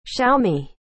Das wird "schiaumi" ausgesprochen oder man kann auch das i verschlucken und "schaumi" sagen.